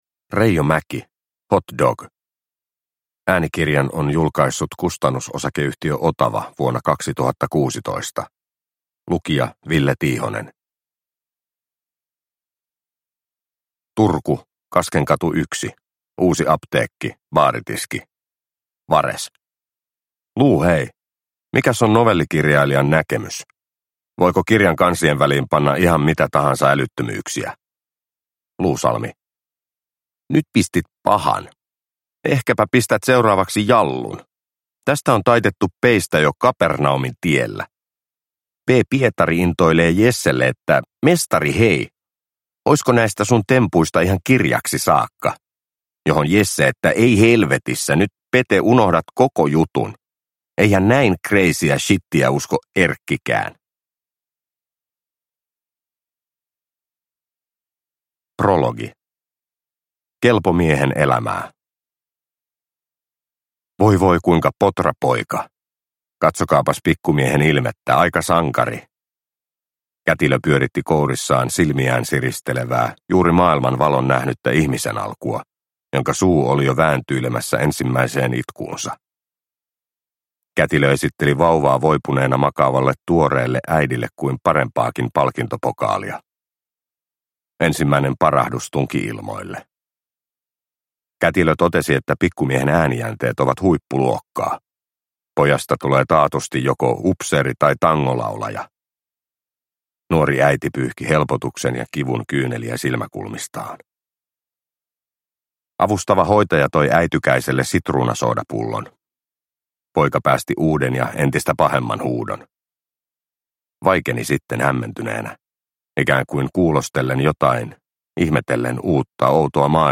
Hot dog – Ljudbok – Laddas ner